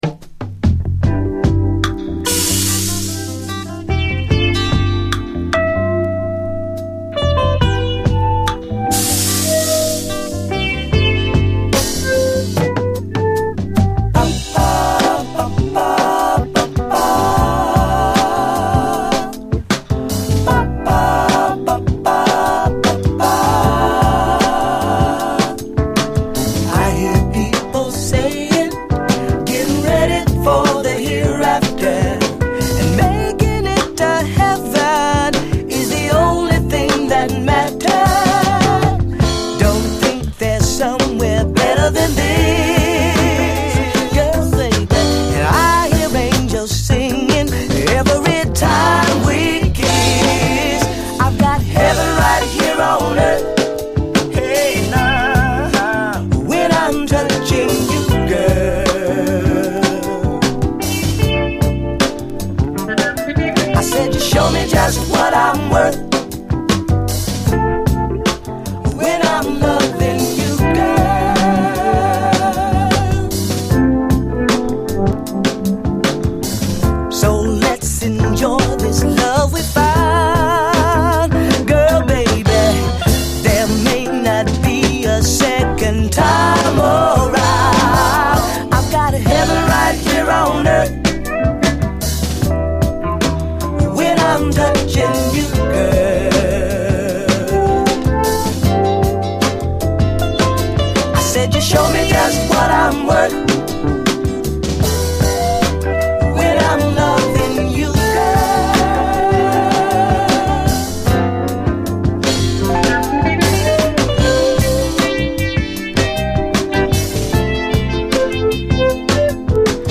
SOUL, 70's～ SOUL